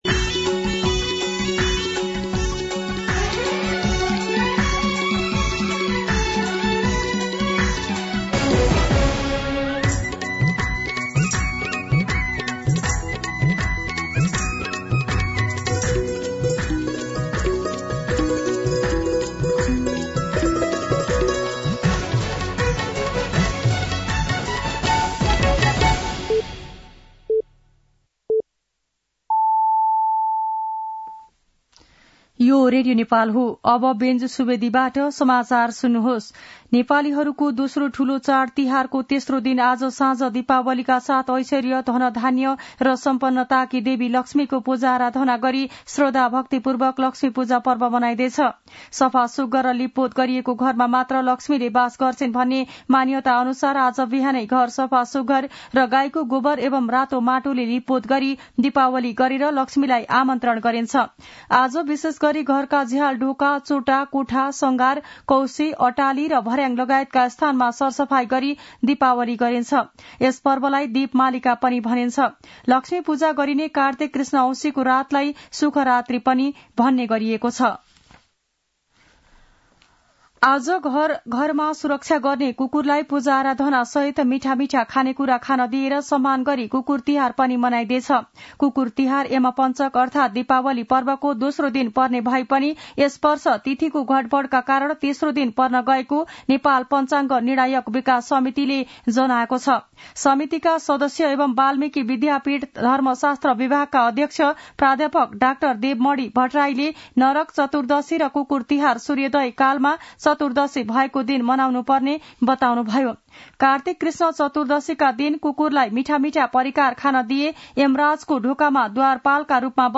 दिउँसो १ बजेको नेपाली समाचार : १६ कार्तिक , २०८१
1pm-News-07-15.mp3